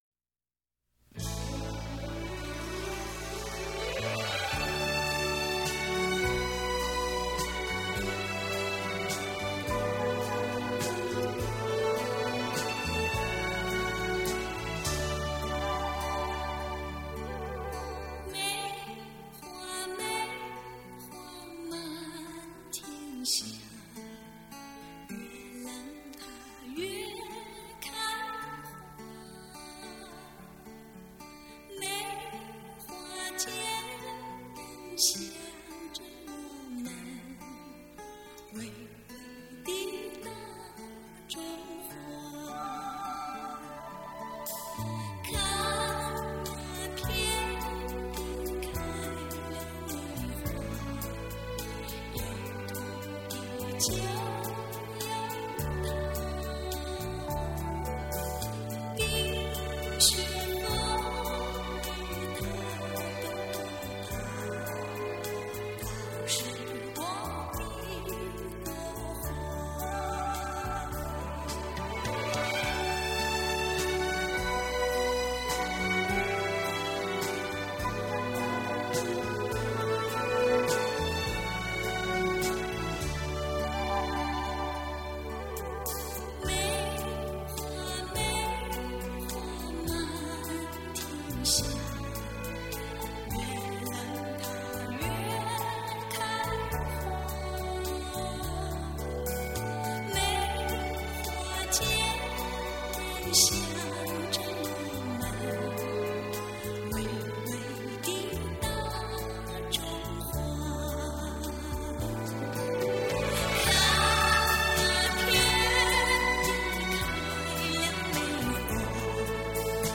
脍炙人口怀念金曲
温馨甜蜜耐人寻味